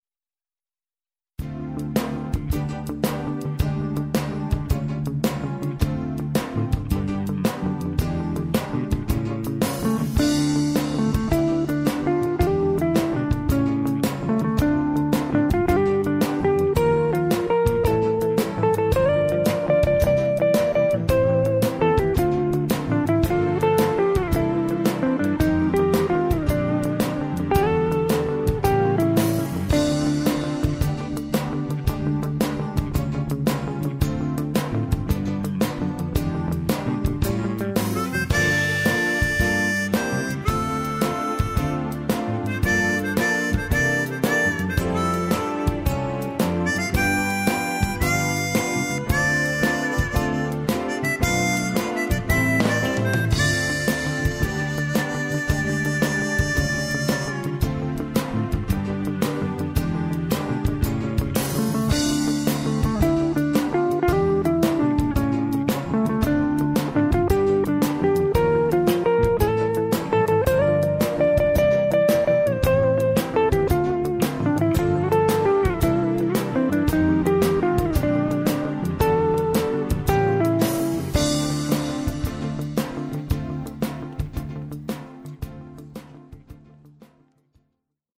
Вырезку из фильма сделал, прилагаю.